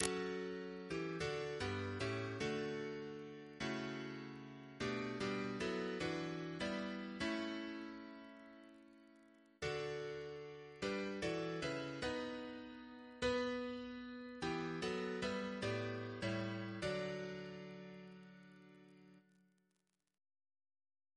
Double chant in D Composer: Richard Wayne Dirksen (1921-2003), Organist of Washington Cathedral Note: for Psalm 121